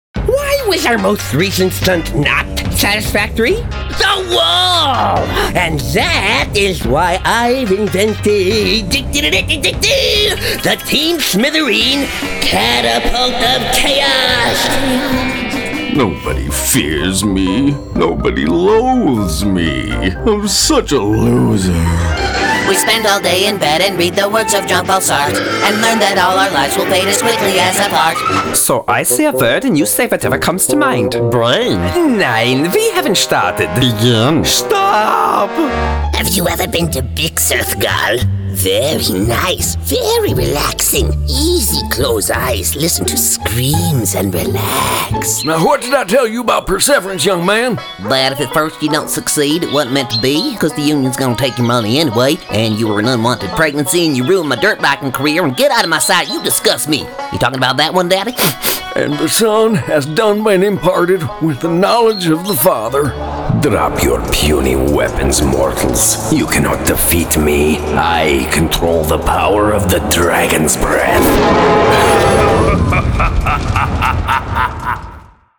Animation VO Demo